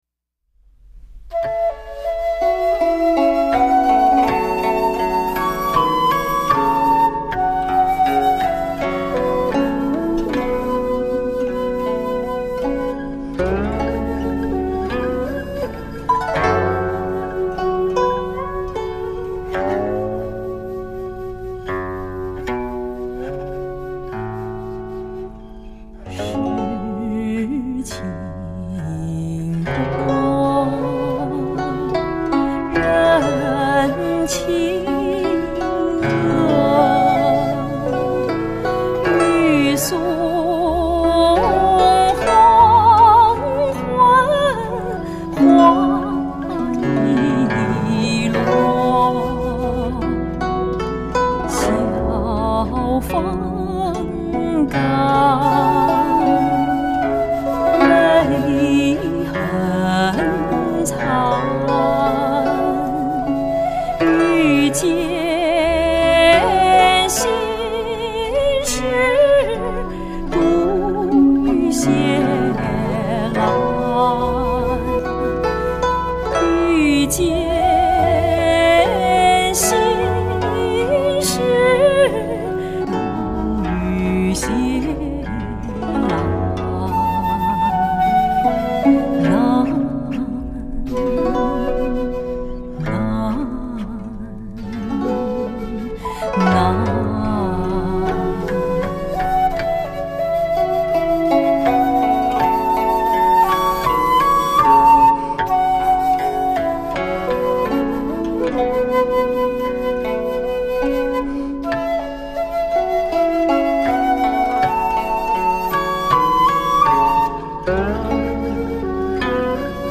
最高质素的录音制作、最深入的中国传统艺术表现力！